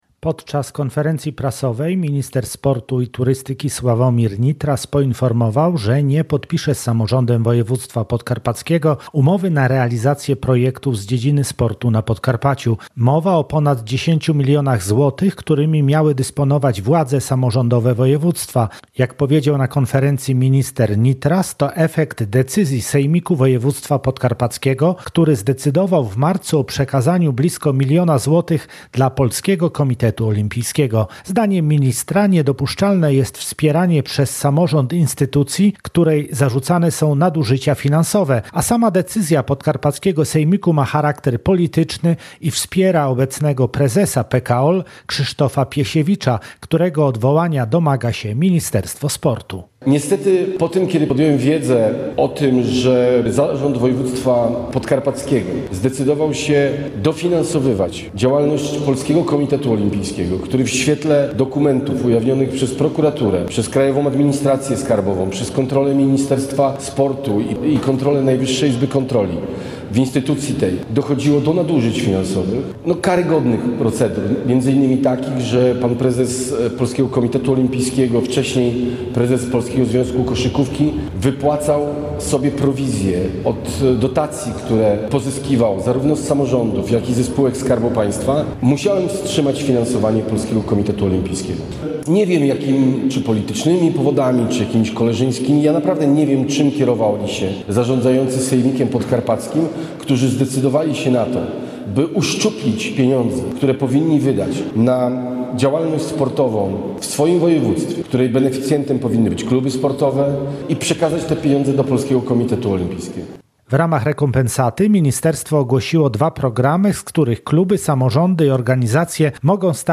Jak powiedział na konferencji minister Sławomir Nitras – to skutek decyzji Sejmiku Województwa Podkarpackiego, który zdecydował w marcu o przekazaniu blisko miliona złotych dla Polskiego Komitetu Olimpijskiego: